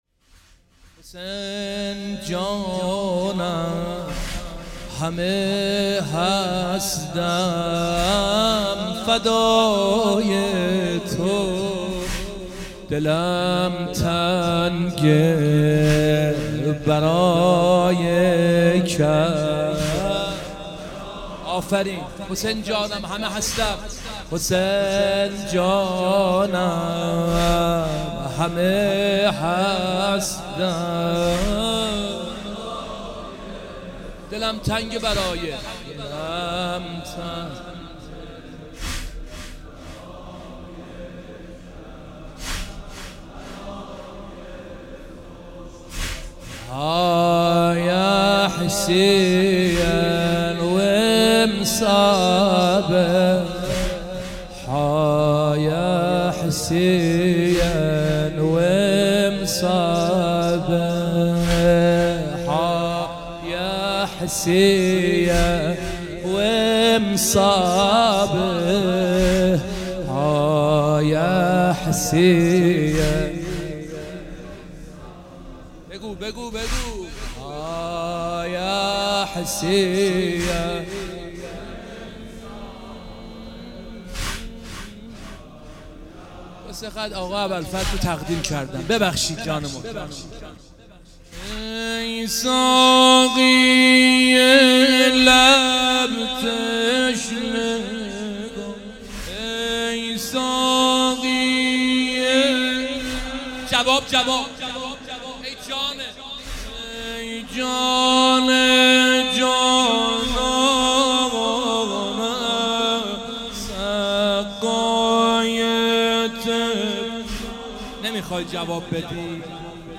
مداحی حاج حسین سیب سرخی و حاج مجید بنی فاطمه در خمینی شهر